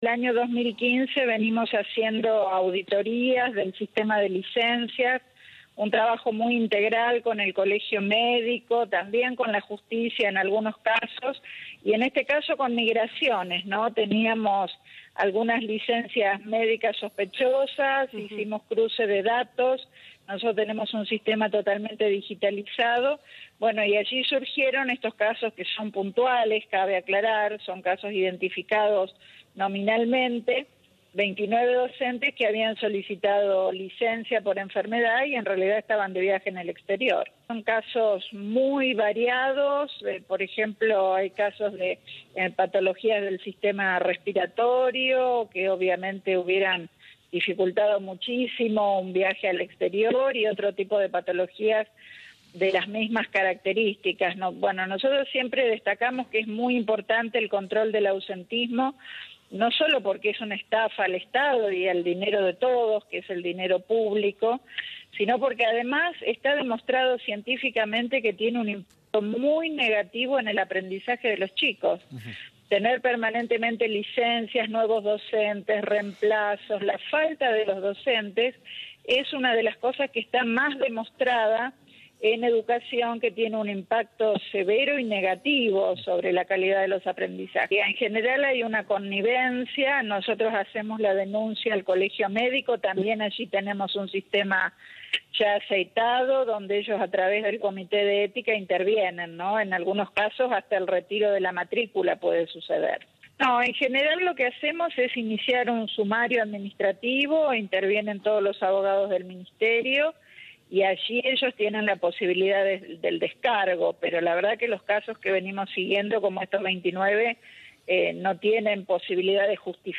En diálogo con Telefé Noticias, Claudia Balagué, ministra de Educación de Santa Fe, explicó cómo descubrieron estos casos y cuáles serán los pasos a seguir.